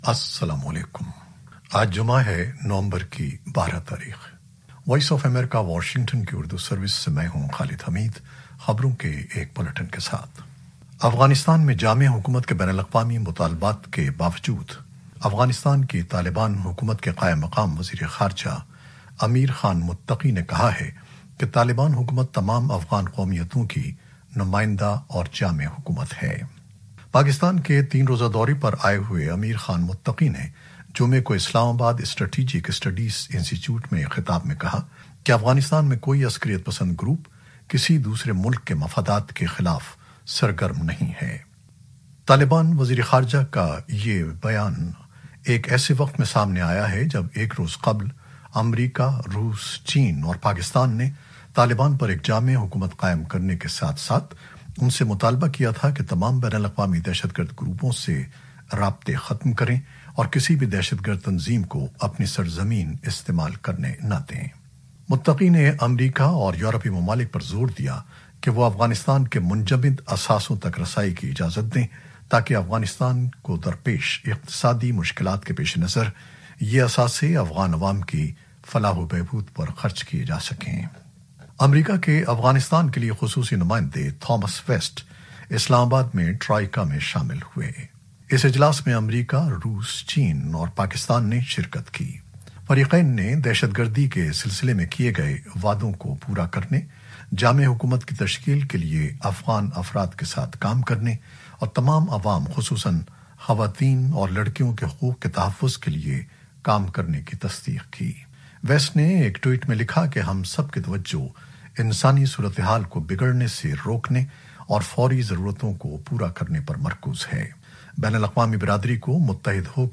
نیوز بلیٹن 2021-12-11